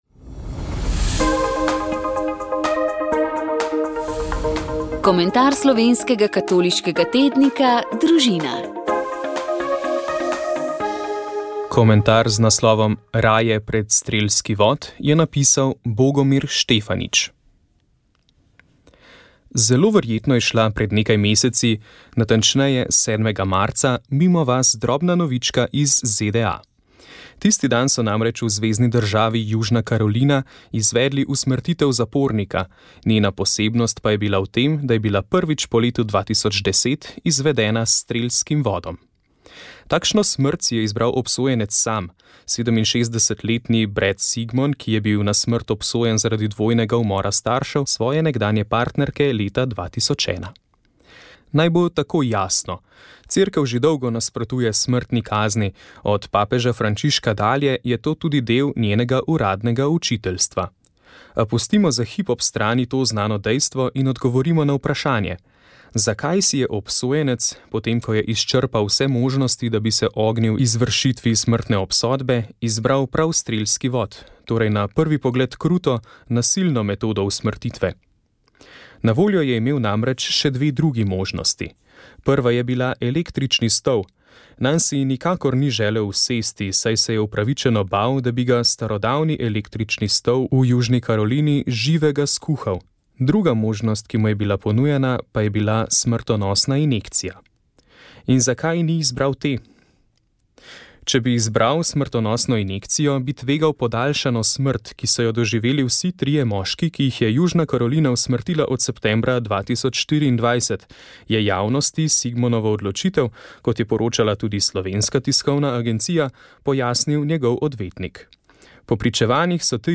Obisk živalskega vrta je vedno nekaj posebnega. Obiskali smo ga z mikrofonom in Mavrico. Ta bo v novem letu gostila tudi rubriko, ki bo govorila o domačih živalih in skrbi zanje.